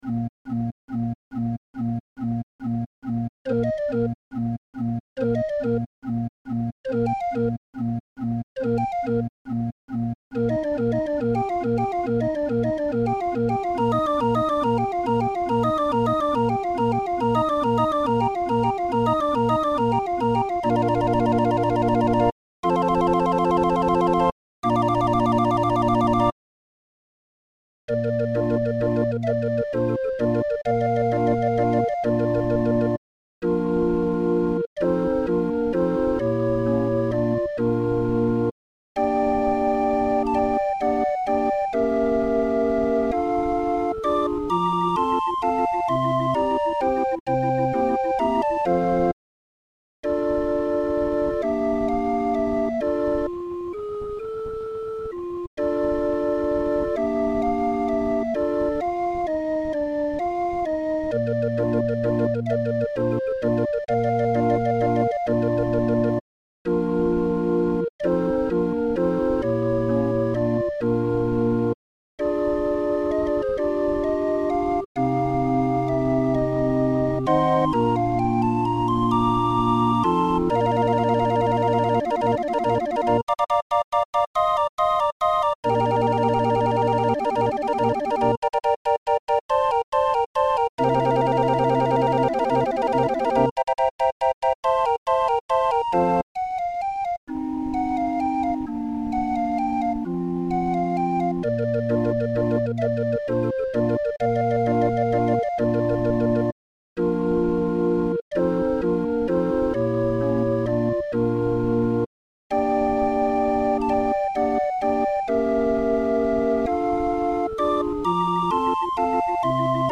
Musikrolle 31-er